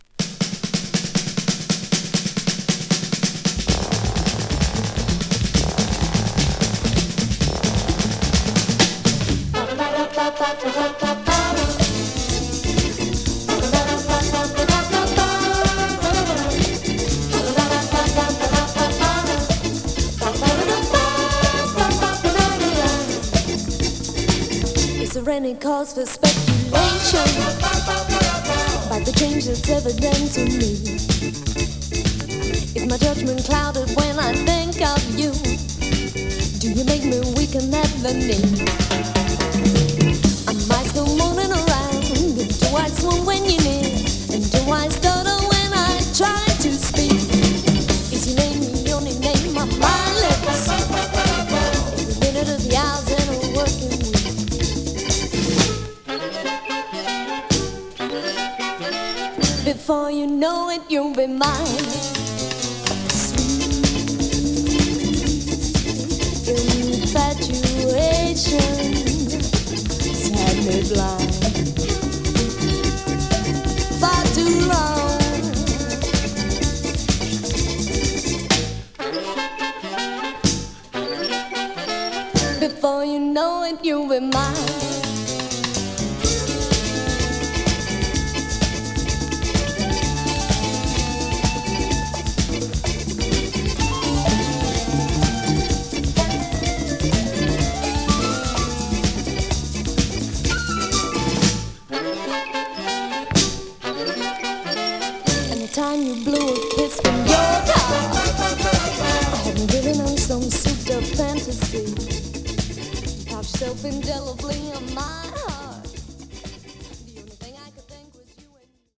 ラテンフュージョン的なボーカルナンバー